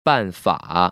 [bànfă] 빤파